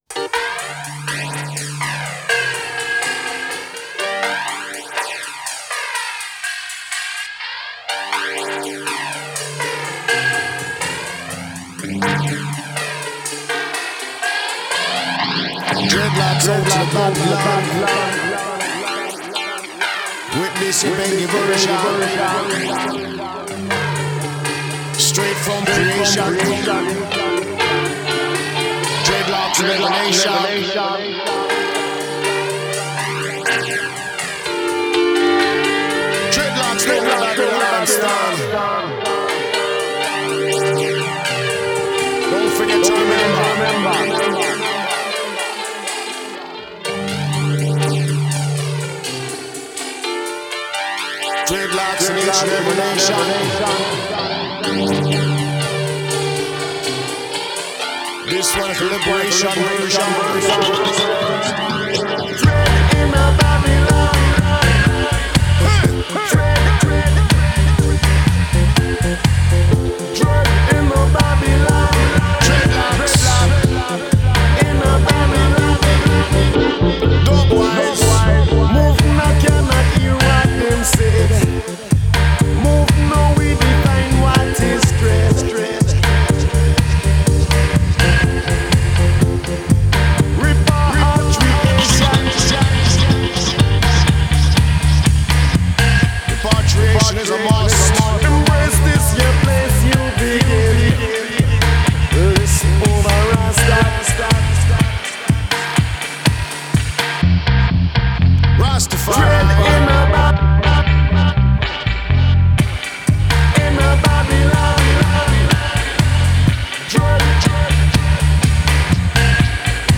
Genre: Reggae, Dub.